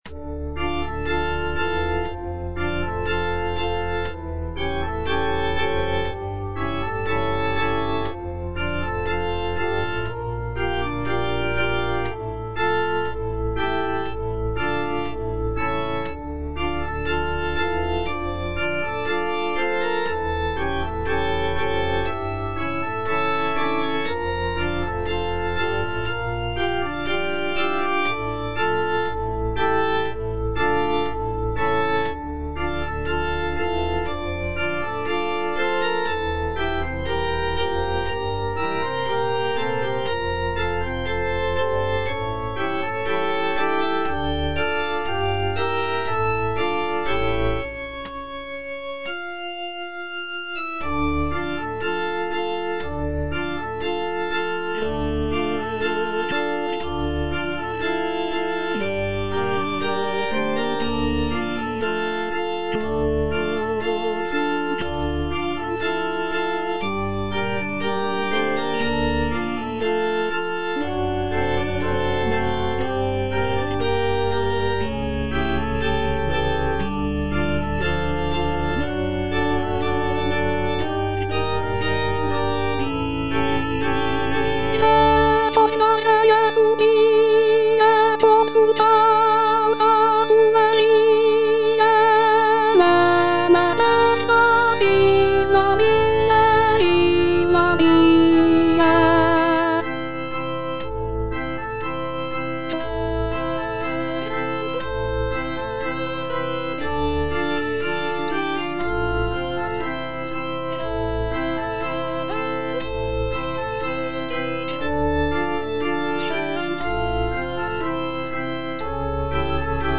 F. VON SUPPÈ - MISSA PRO DEFUNCTIS/REQUIEM Recordare - Soprano (Voice with metronome) Ads stop: auto-stop
The piece features lush harmonies, soaring melodies, and powerful choral sections that evoke a sense of mourning and reverence.